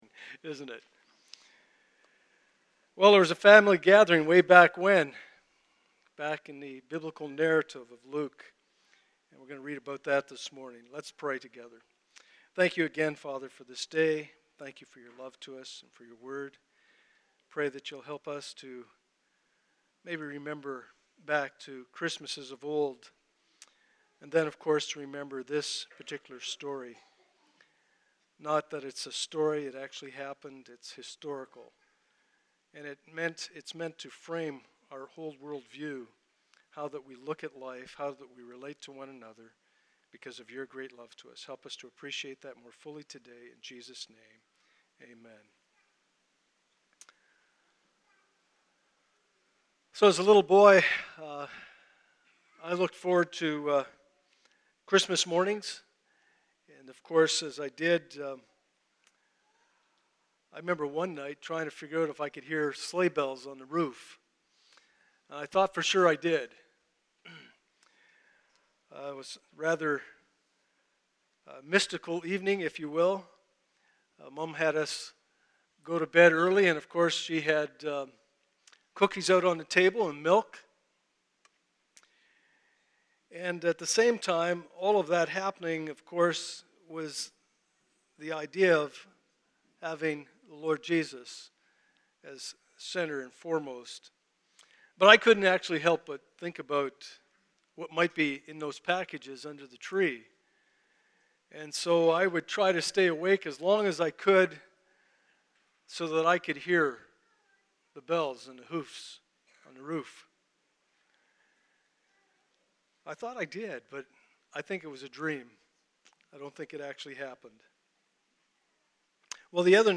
Passage: Luke 2:8-14 Service Type: Sunday Morning